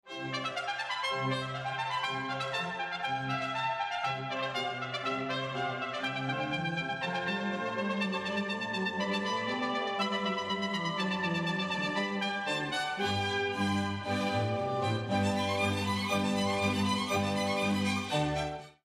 Concerto pour 2 trompettes & orchestre